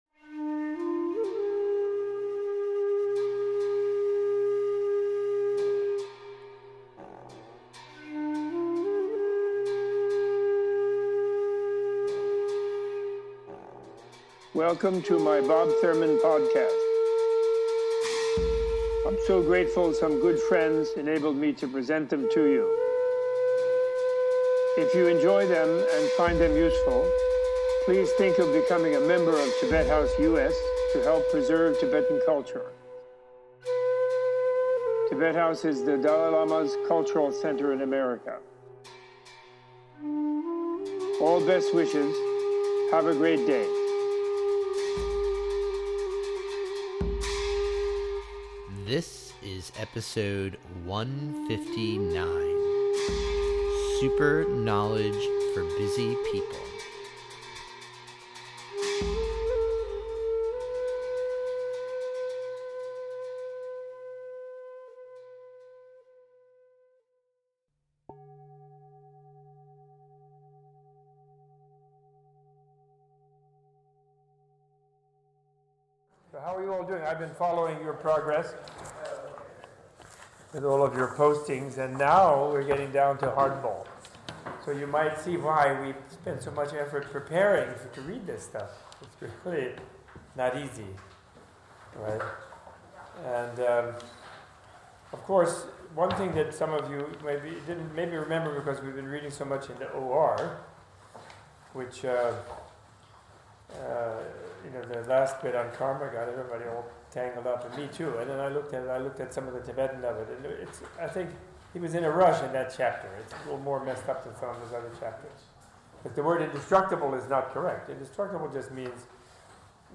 In this recording from his early days at Columbia University Professor Thurman explores Indo-Tibetan Buddhism in the intellectual setting of ancient India and Tibet, how it relates to modern philosophical thought and to the mindfulness revolution in popular culture.